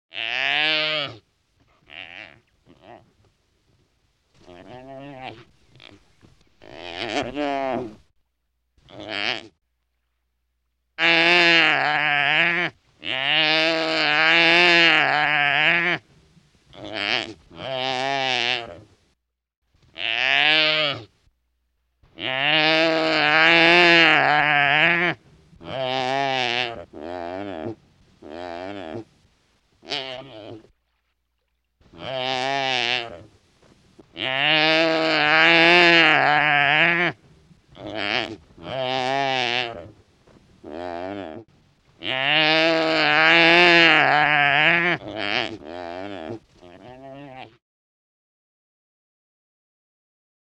دانلود صدای گاو وحشی 1 از ساعد نیوز با لینک مستقیم و کیفیت بالا
جلوه های صوتی